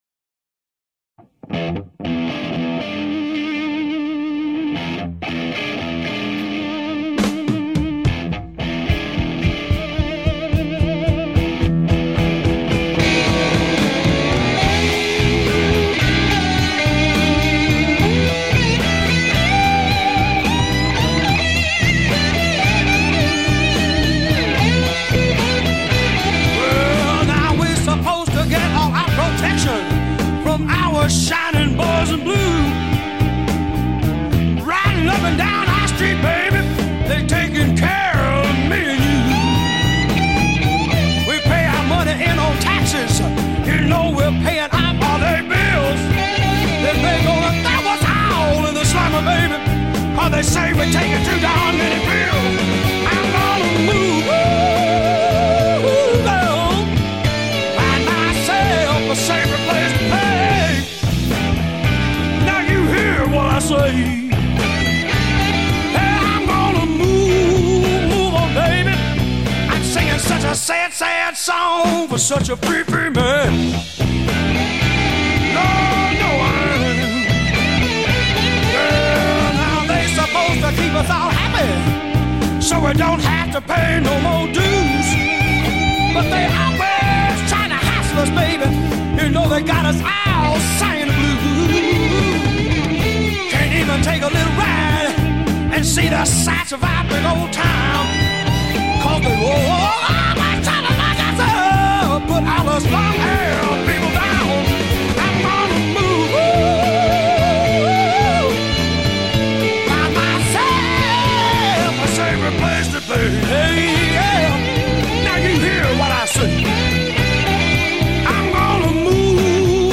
American rock and roll band